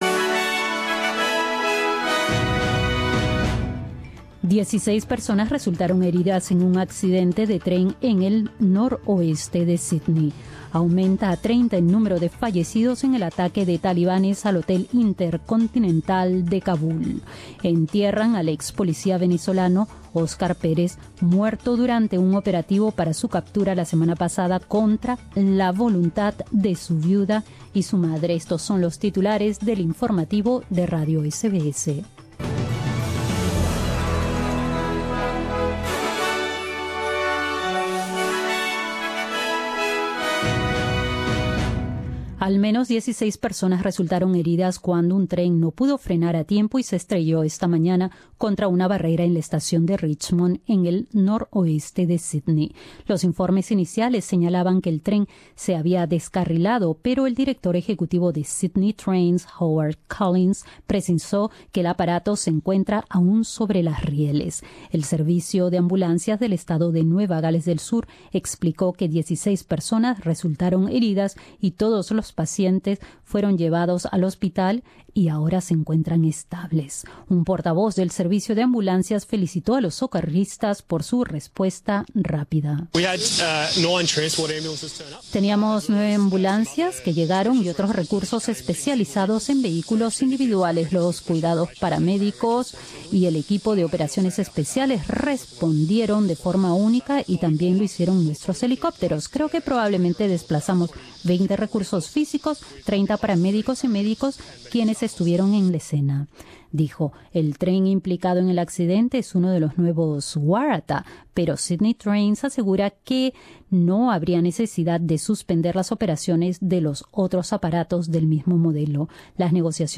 Noticias australianas del 22 de enero de 2018